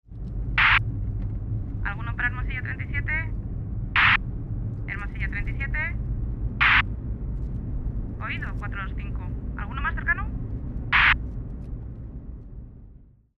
Radio de llamada de un taxi
Sonidos: Comunicaciones Sonidos: Voz humana Sonidos: Transportes Receptor de radio